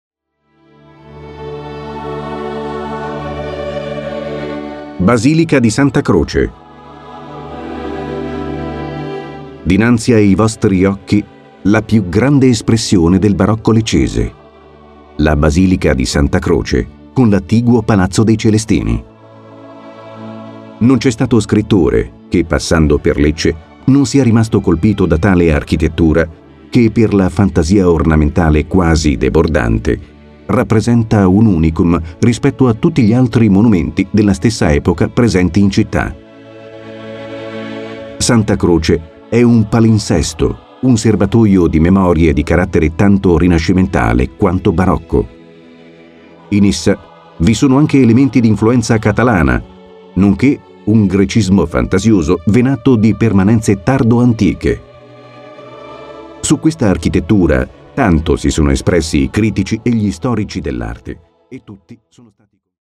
Happy Tourist è la nuova audio guida turistica digitale
Ascolta la DEMO di un commento di un Opera in Italiano, Inglese o Francese e scopri la bellezza delle descrizioni e la loro particolarità.